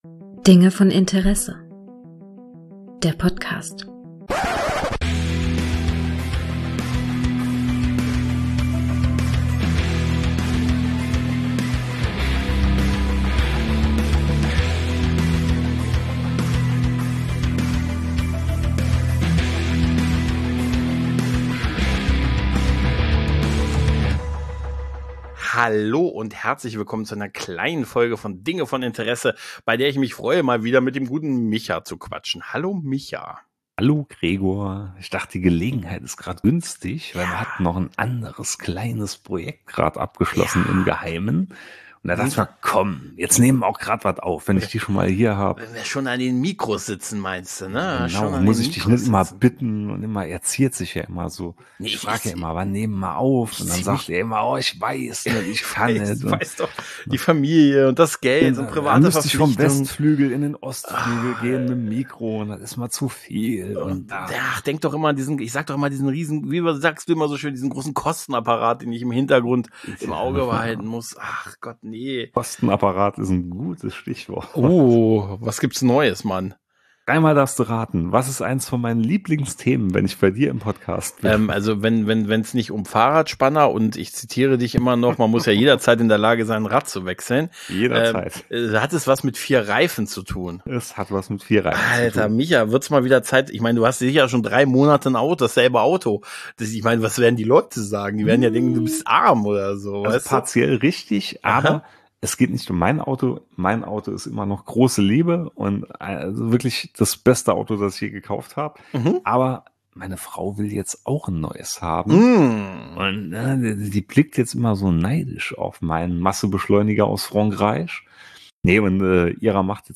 am Rande einer anderen Aufnahme noch etwas "philosophiert".